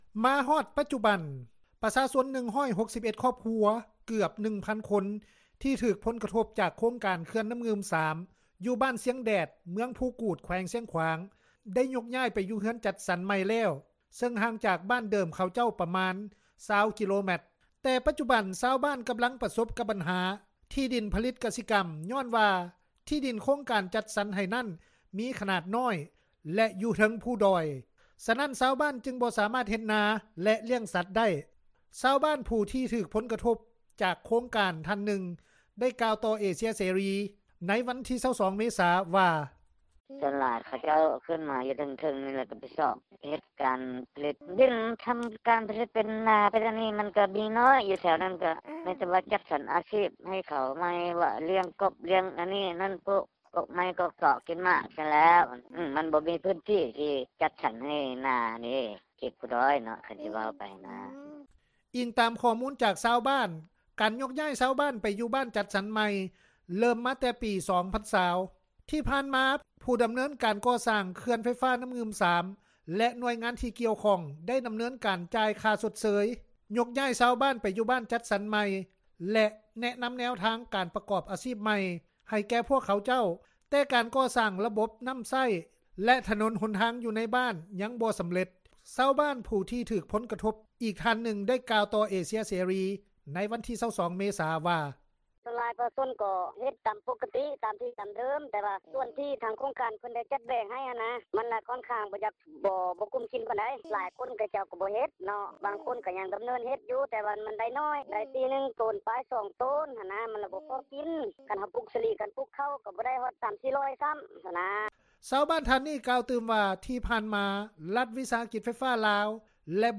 ຊາວບ້ານ ຜູ້ທີ່ຖືກຜົລກະທົບຈາກໂຄງການ ທ່ານນຶ່ງ ກ່າວຕໍ່ວິທຍຸເອເຊັຽເສຣີ ໃນວັນທີ 22 ເມສາ ວ່າ: